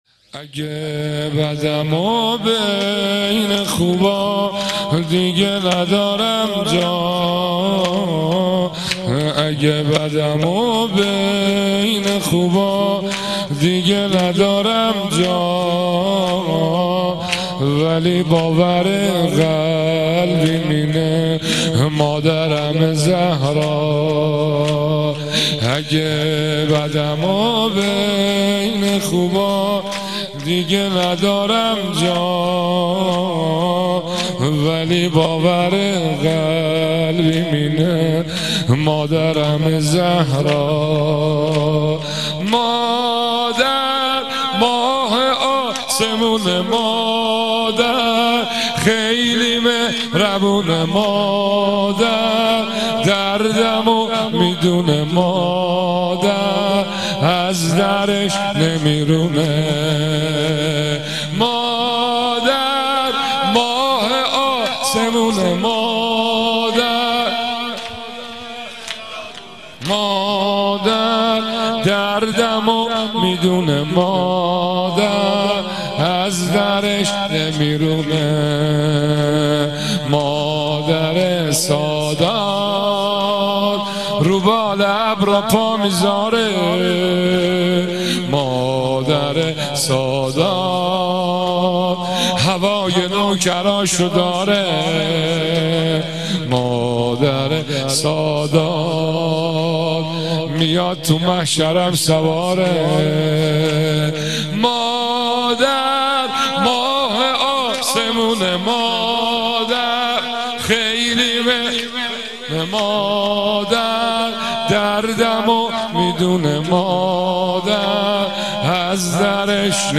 شور | اگه بَدم و بین خوبا دیگه ندارم جا
مداحی
در سالروز شهادت حضرت زهرا (س)